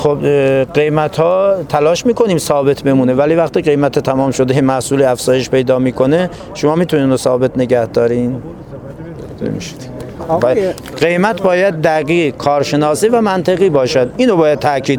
در گفت‌وگو با ایکنا مطرح شد